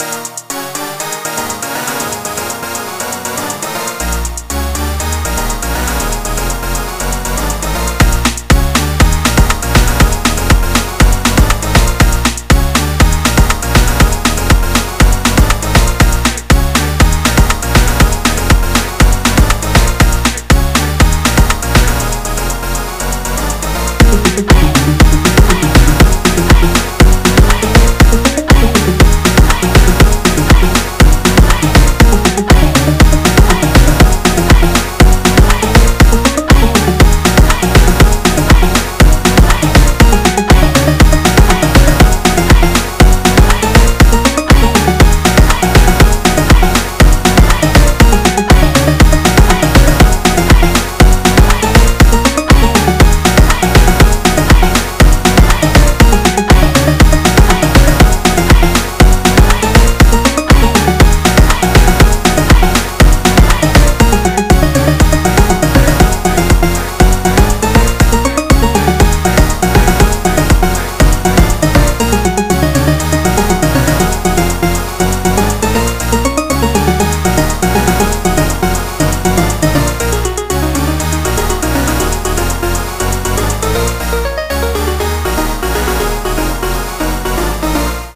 repatitive :p